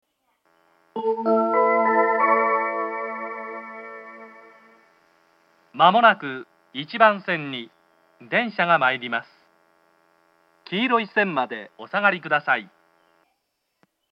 接近放送があり、「東海道型」の放送が使用されています。
１番線接近放送 男声の放送です。